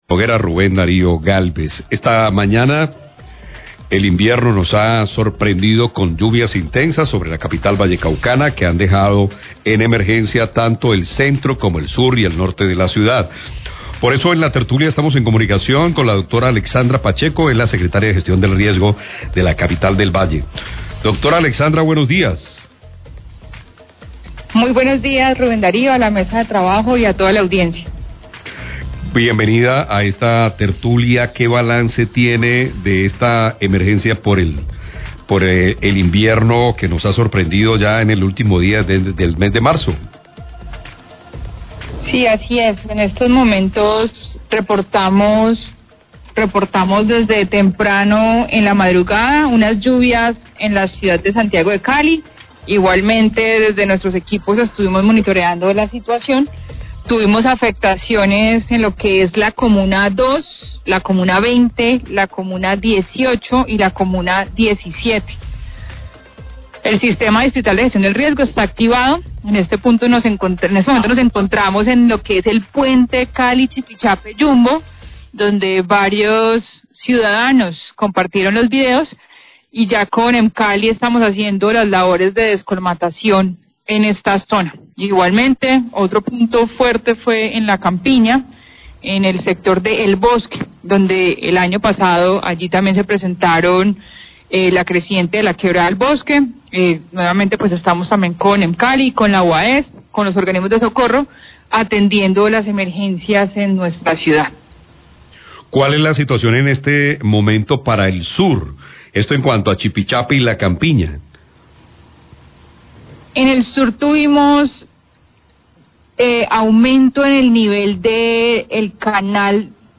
Radio
Secretaria de Gestión del Riesgo, Alexandra Pacheco, entregó un balance acerca de las afectaciones por las fuertes lluvias presentadas el día de hoy en Cali.